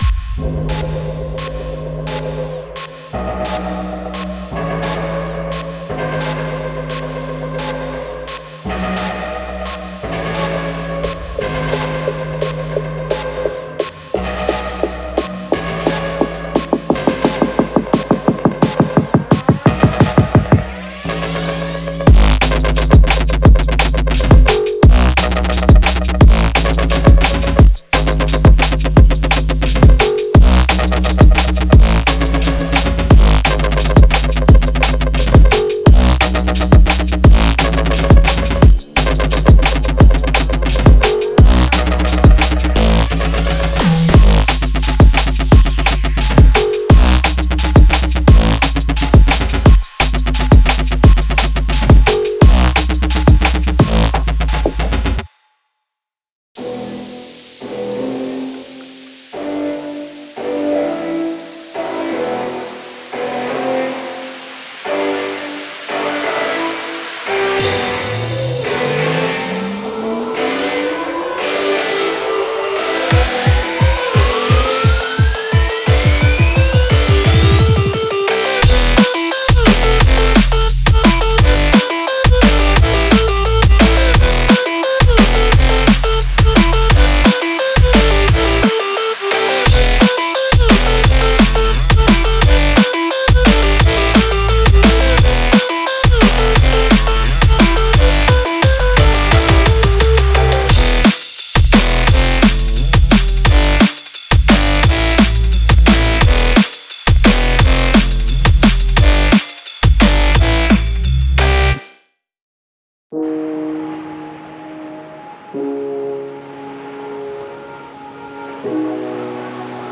Genre:Drum and Bass
デモサウンドはコチラ↓